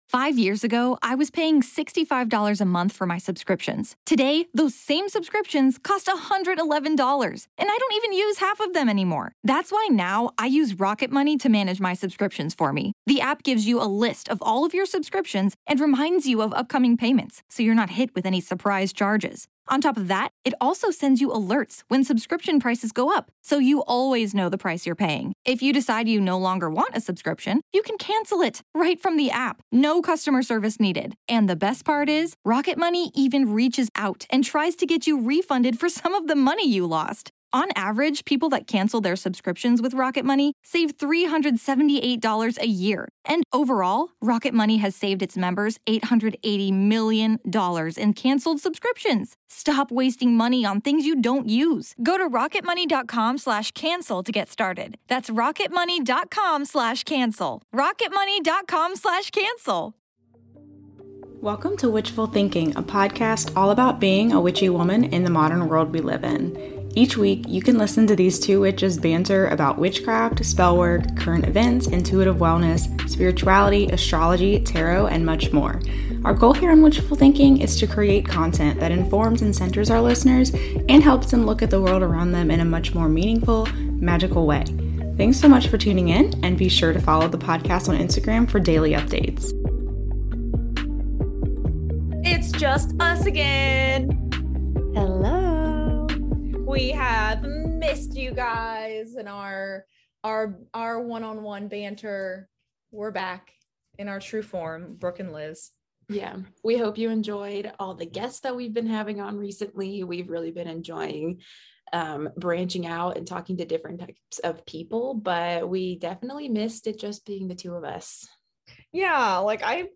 Today we're taking it back to a good ole shoot the sh-t conversation. We asked listeners to send us their biggest red flags in relationships and in friendships, and this is what we got!